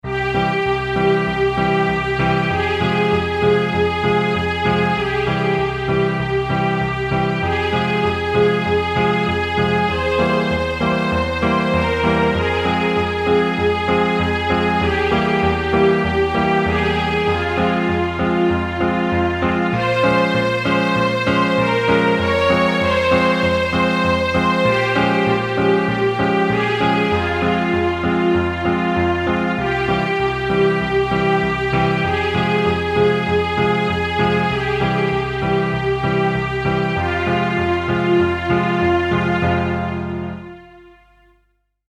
Música orquestal melancólica
melancólico música orquestal